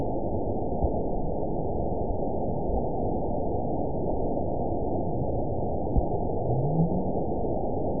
event 920380 date 03/20/24 time 21:57:39 GMT (1 year, 1 month ago) score 9.23 location TSS-AB04 detected by nrw target species NRW annotations +NRW Spectrogram: Frequency (kHz) vs. Time (s) audio not available .wav